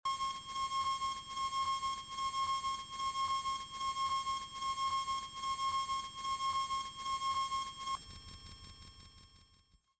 A segment of the "Glass Pour" audio, transposed to a higher frequency, with an added audio filter onto it. This sound is correlated with the letter "k" on the computer keyboard.
Edited with and exported from Abletone Live.